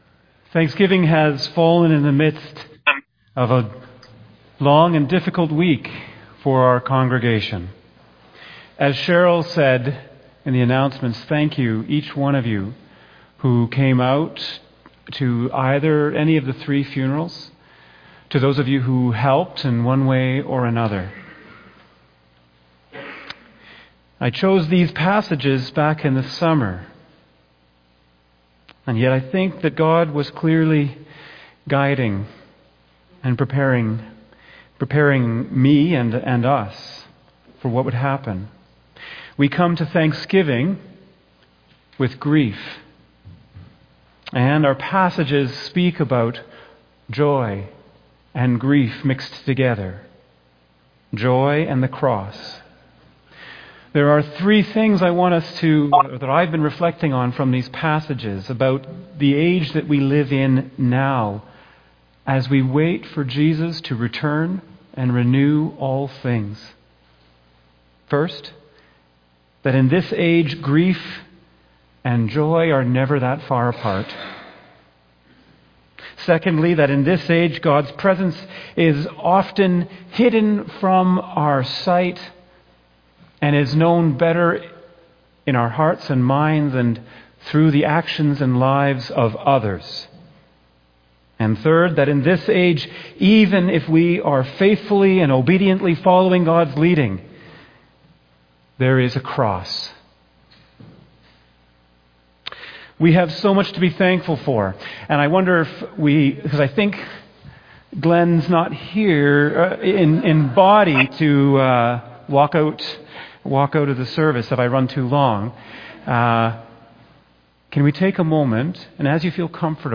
2025 Sermon October 12 2025
JOIN our Worship Service: 11:00 am